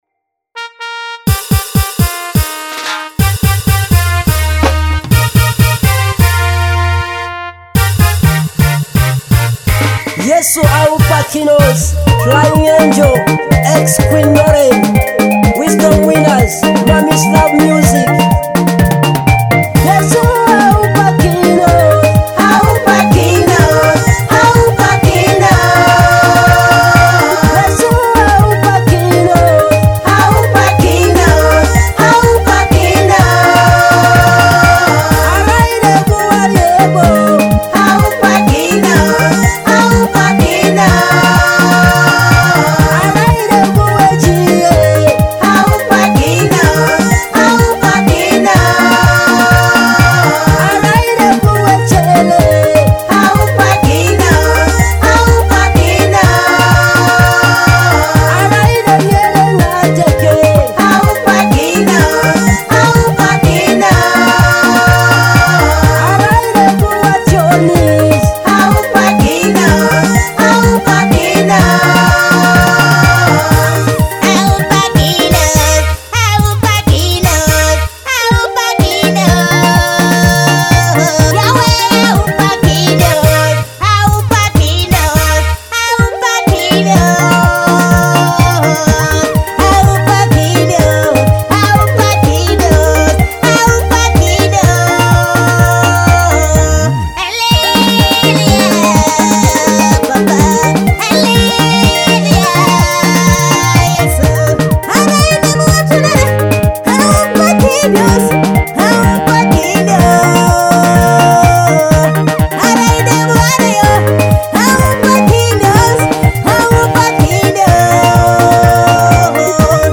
a gospel anthem